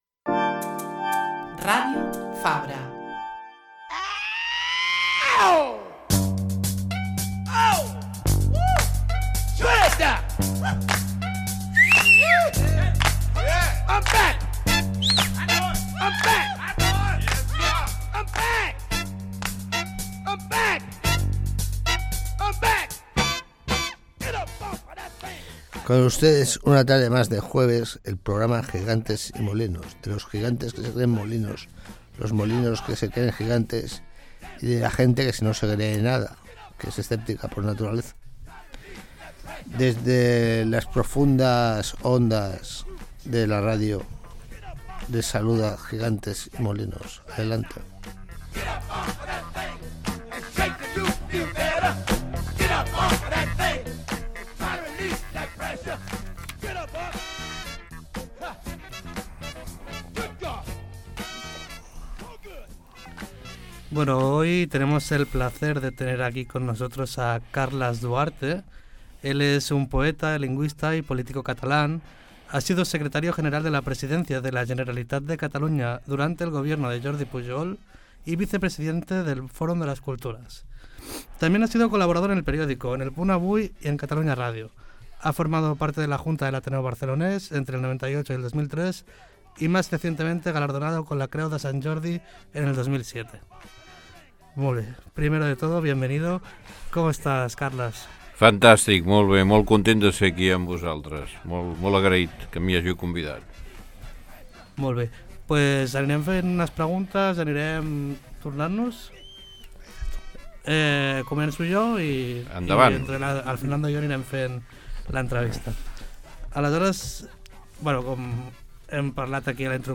Entrevista a Carles Duarte, juegos poéticos y momias